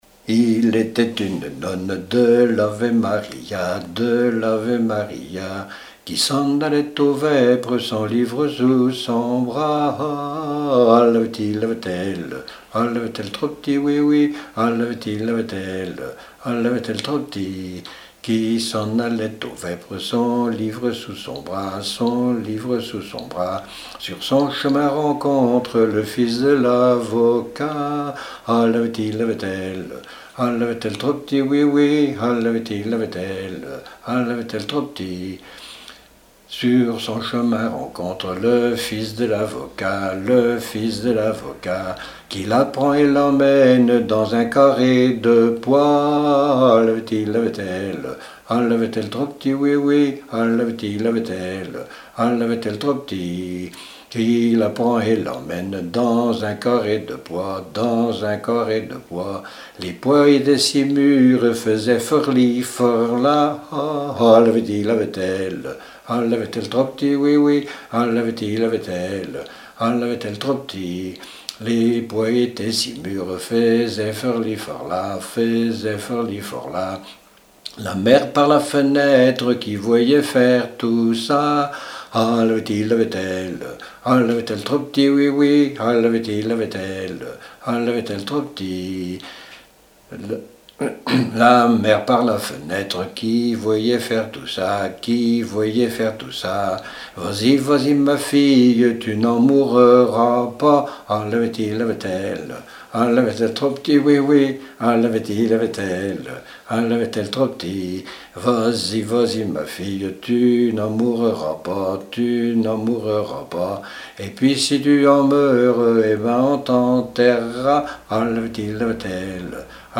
Mémoires et Patrimoines vivants - RaddO est une base de données d'archives iconographiques et sonores.
Genre laisse
Répertoire de chants brefs pour la danse
Pièce musicale inédite